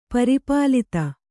♪ pari pālita